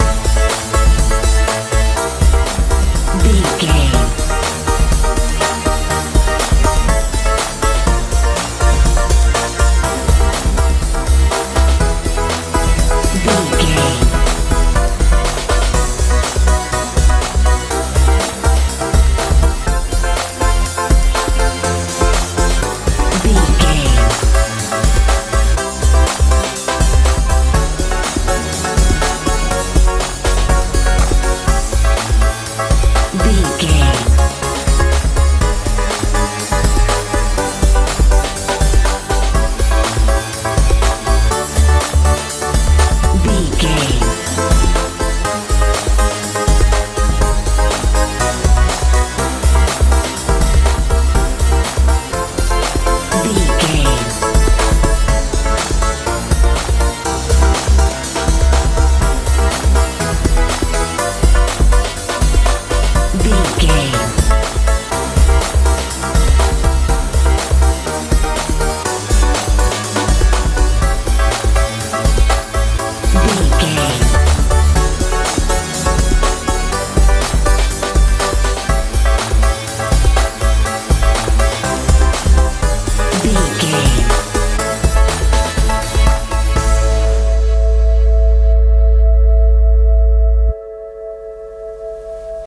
dance feel
Ionian/Major
energetic
motivational
synthesiser
bass guitar
drums
80s
90s
strange